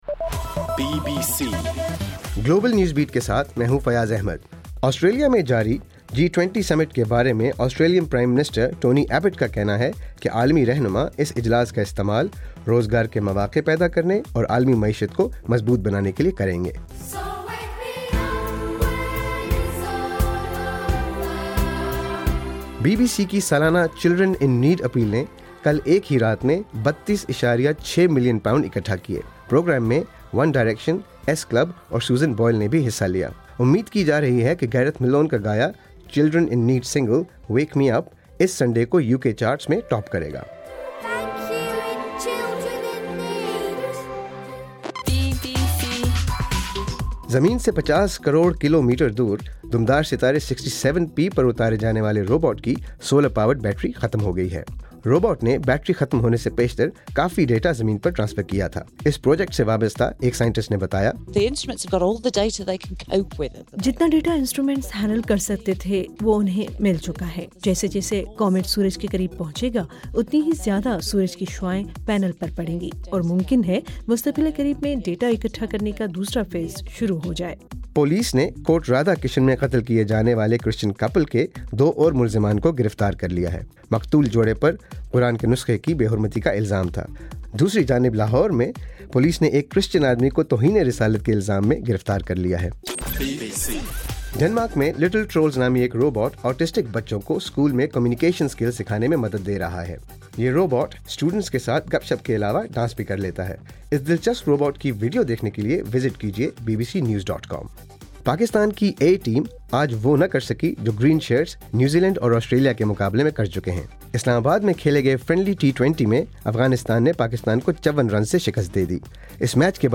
نومبر 15: رات 9 بجے کا گلوبل نیوز بیٹ بُلیٹن